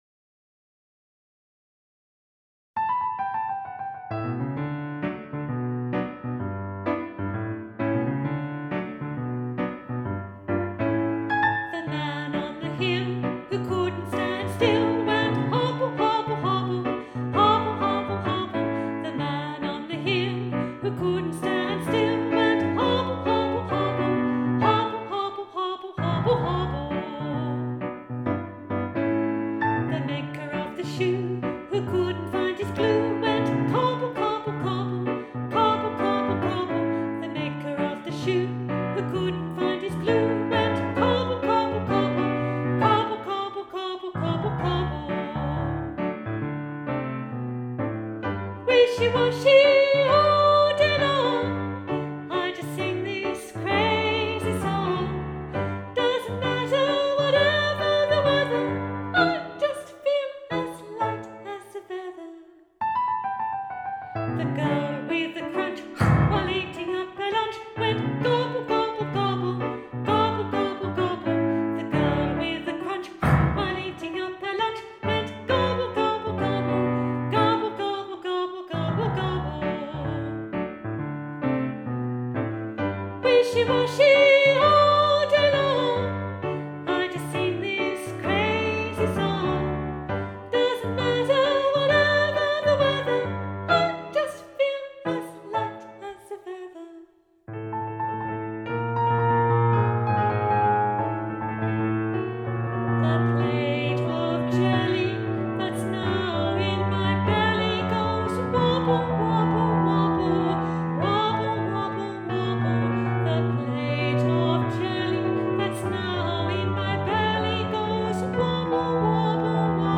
Treble Unison with piano
Live Demo